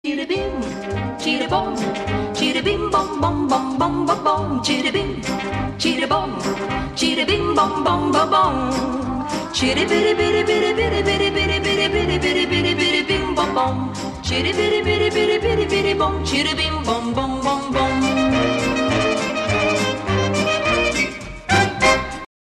Еврейские